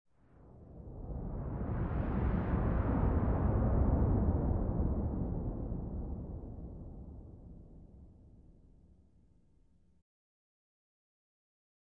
Sports Wave Whoosh
Arena Crowd; Wave Whoosh For Crowd Transition.